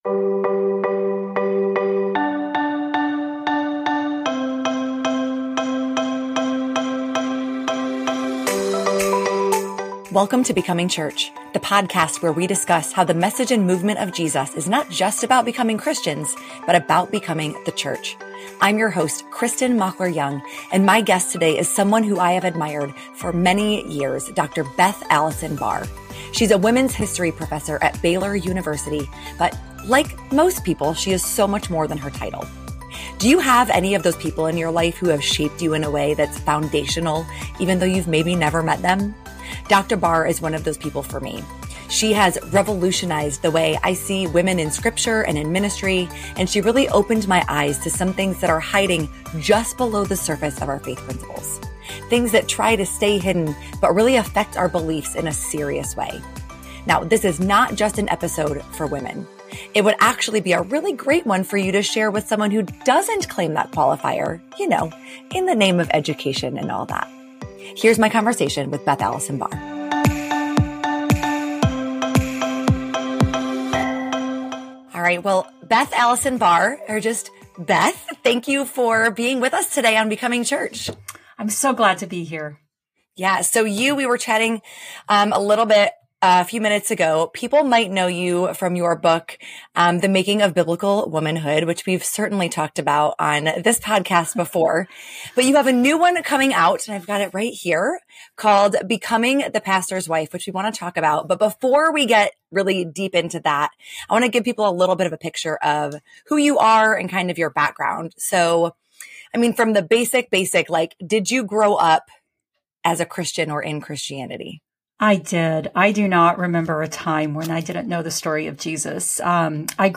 Through her research and lived experience, Dr. Barr has discovered patterns that connect the history of the church to the male-dominated field it is today. This conversation will help you understand why things are the way they are as well as how, and why, we should work for more equality in church leadership.